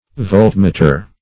Voltmeter \Volt"me`ter\, n. [2d volt + -meter.]